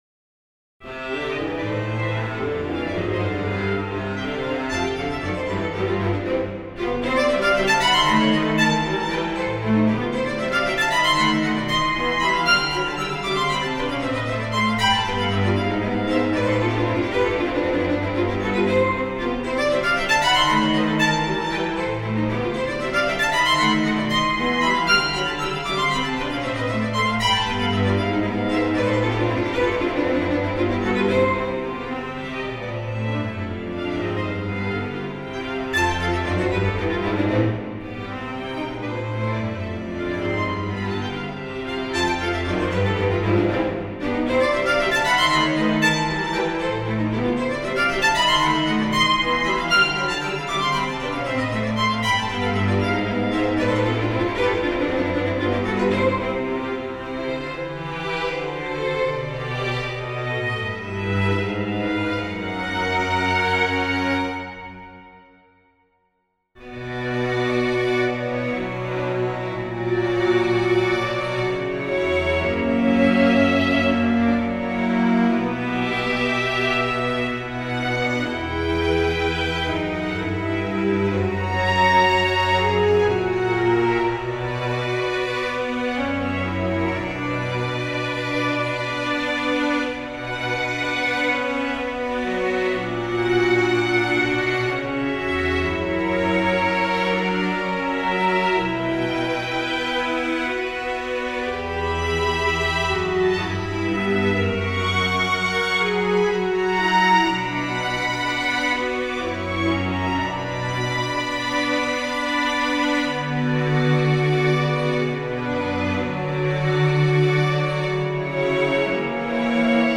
New Waltz in C Major for String Quartet or Piano Quintet
I was trying for section something like Tchaikovsky's Valse-Scherzo but lighter for the first section.
The mp3 is only string quartet for now.